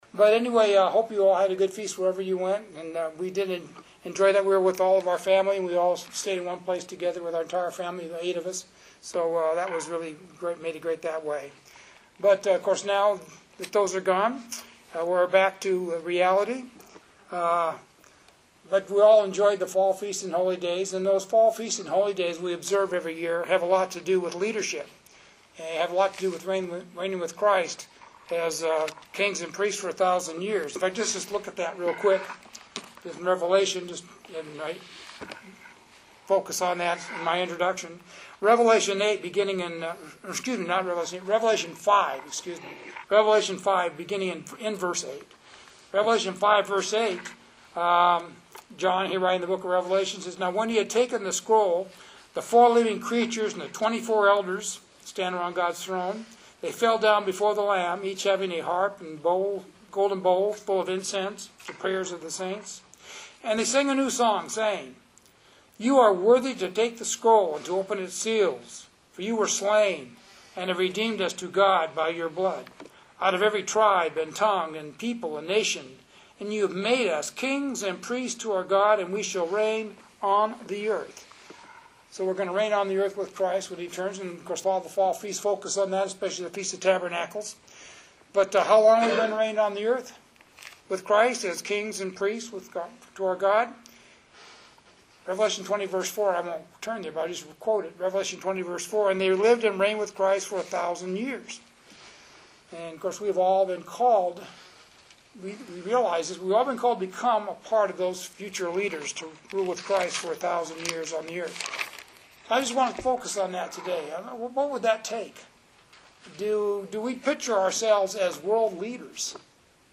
In this sermon we will look at four essential character traits required of a leader.